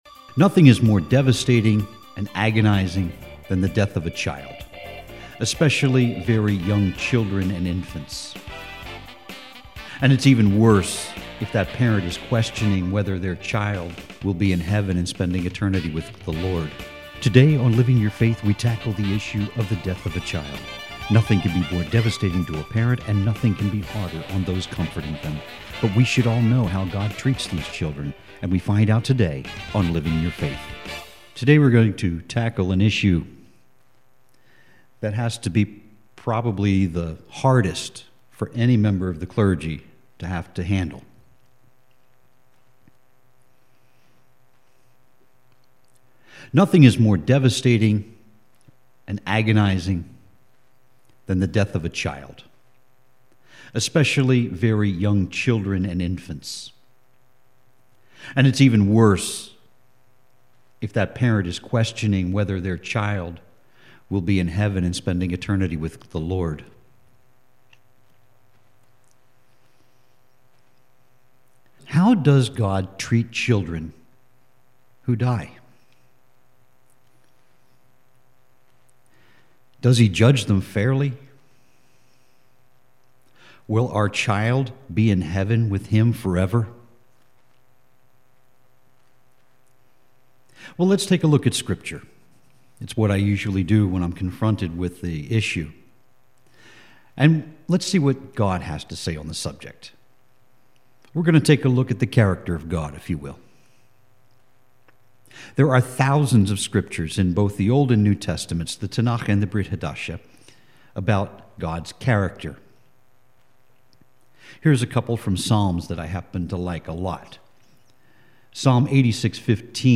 That’s a heavy question – and with the passing of the New York abortion law, and with Virginia almost passing a law that permitted abortion of viable babies up to birth… and after viewing the movie “Gosnell” – I felt that I needed to dust off a sermon I did a few years ago.